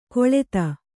♪ koḷeta